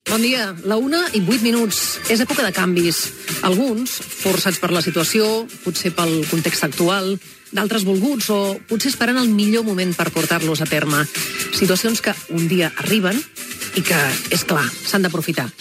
Presentació inicial del primer programa
Info-entreteniment
FM